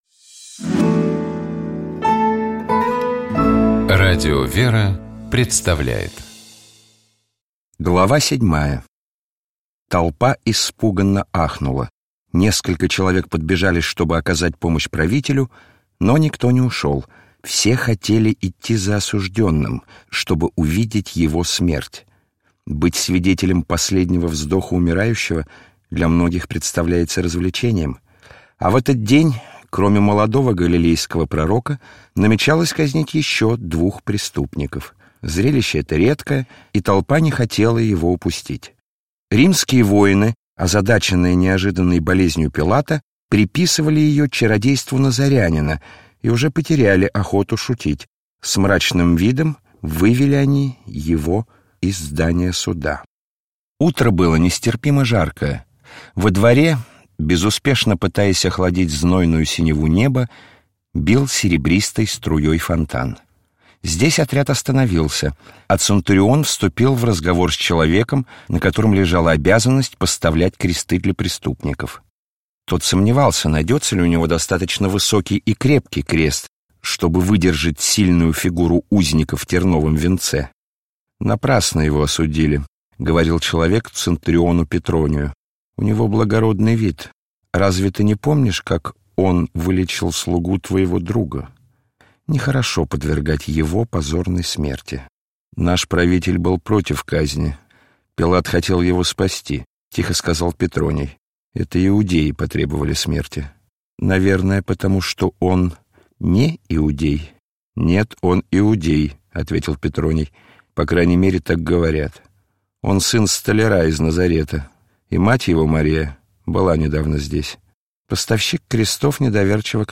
ГлавнаяПрограммыАудиокнигиВаравва. Повесть времён Христа (М. Корелли)